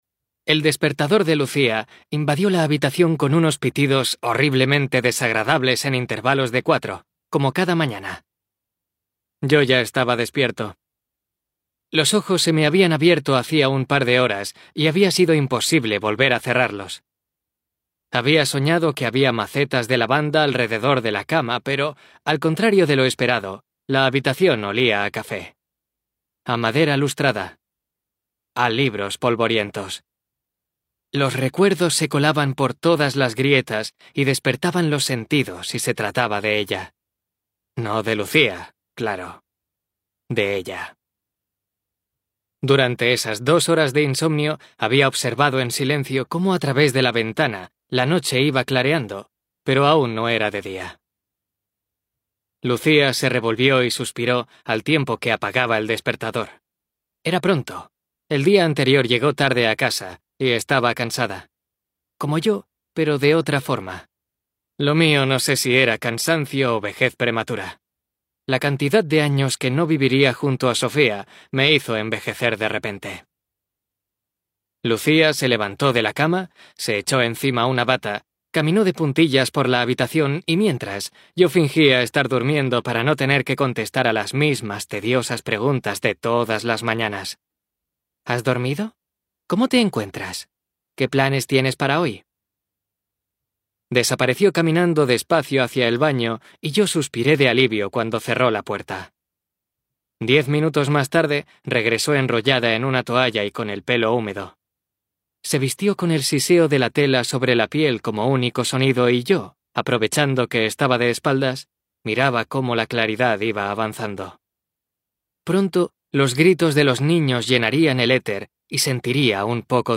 TIPO: Audiolibro CLIENTE: Penguin Random House Grupo Editorial Audio ESTUDIO: Booka Audiobooks